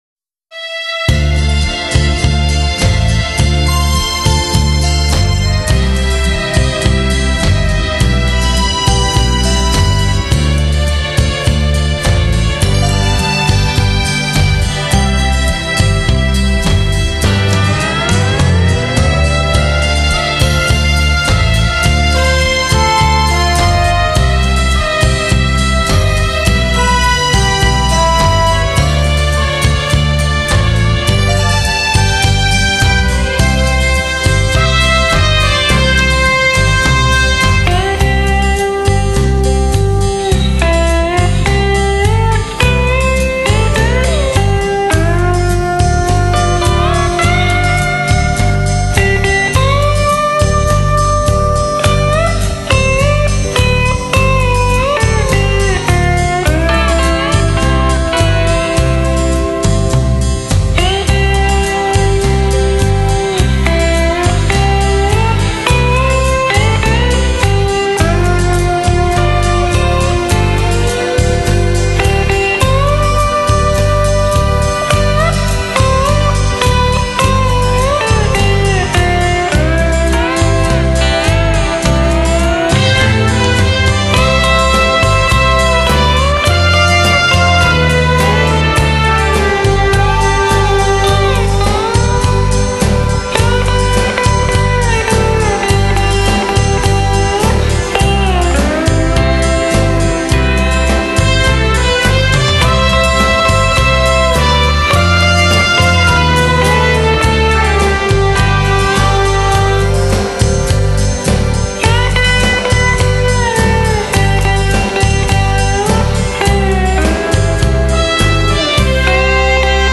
(低品质64k.wma)